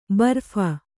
♪ barpha